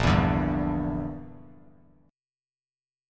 A#6b5 chord